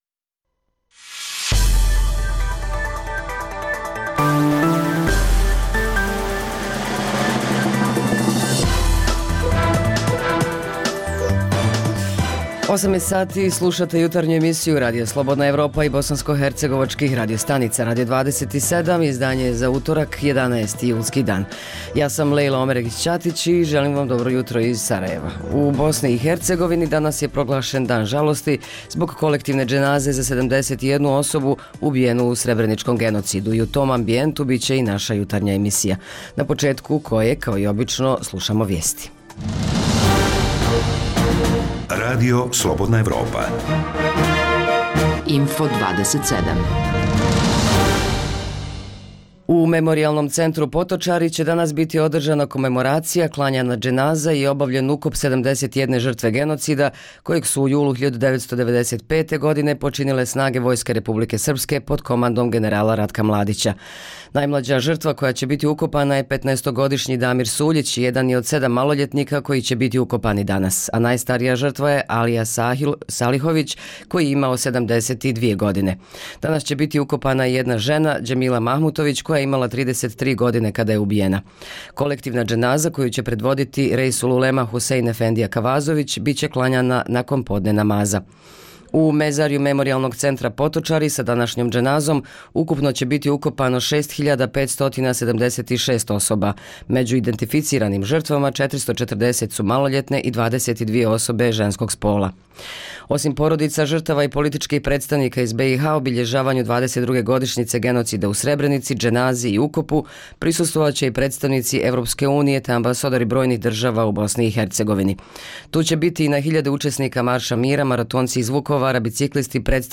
U Memorijalnom centru Potočari će danas biti održana komemoracija, klanjana dženaza i obavljen ukop 71 žrtve genocida, kojeg su u julu 1995. godine počinile snage Vojske Republike Srpske, pod komandom generala Ratka Mladića. Uživo ćemo do Potočara, čućemo tokom emisije potresna svjedočenja preživjelih, a i neke priče koje govore o odnosu današnjih vlasti prema njima.